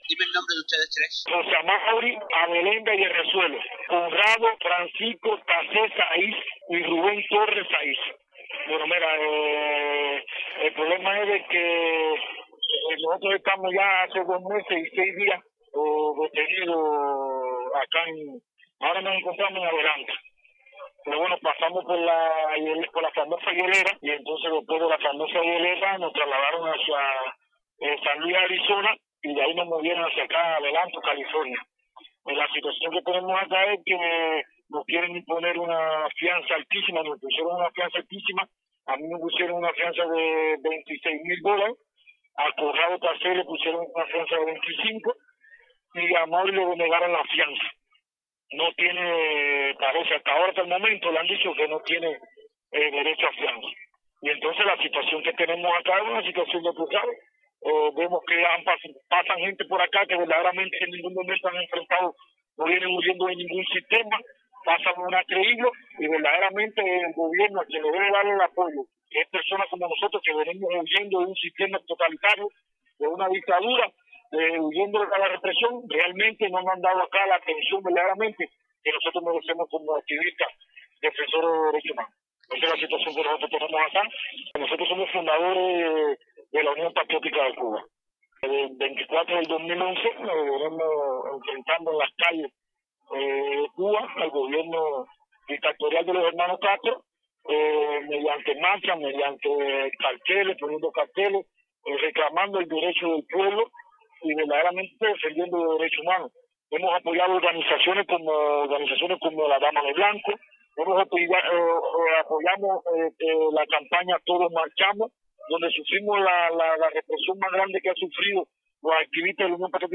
Tres activistas de Derechos Humanos que se opusieron al régimen cubano y buscan refugio en Estados Unidos, permanecen detenidos en una prisión federal en California, según relataron en entrevista con Radio Televisión Martí.